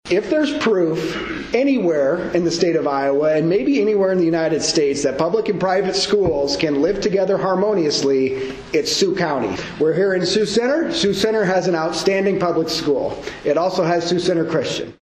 Gregg addressed a crowd in Sioux Center on Wednesday.